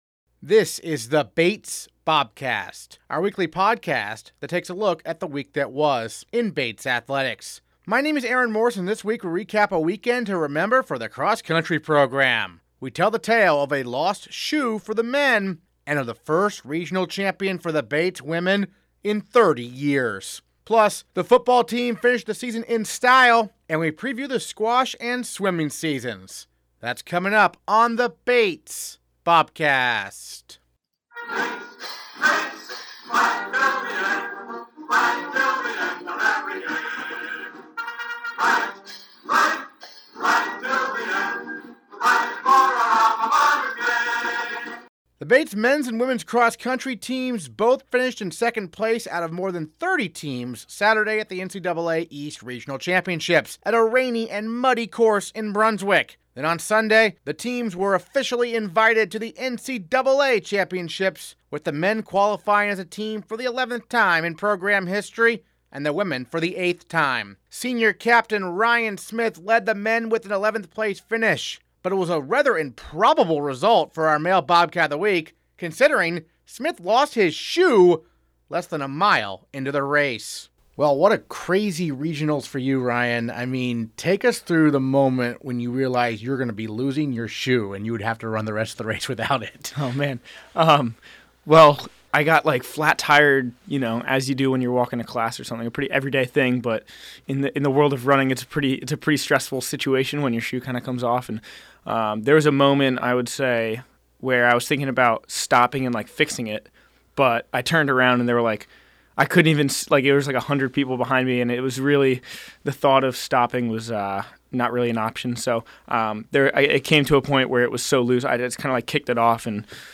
Bobcast Transcript Interviews this episode